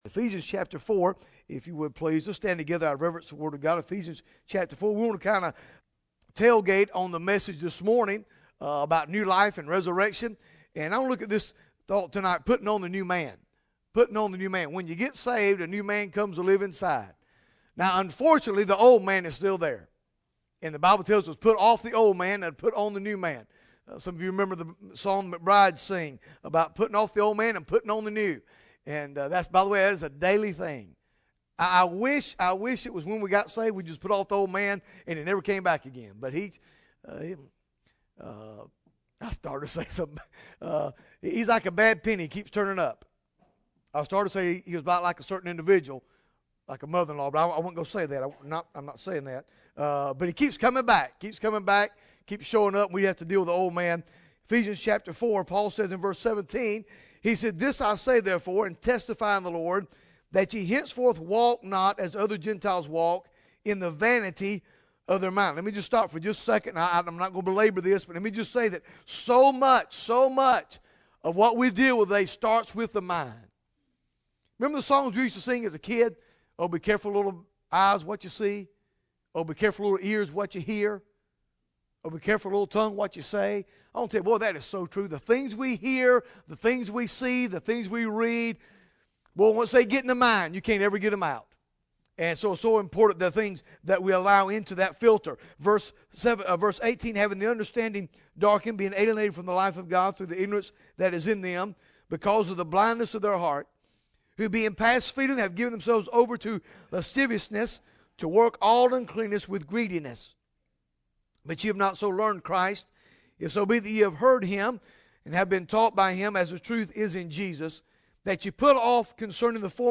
Bible Text: Ephesians 4 | Preacher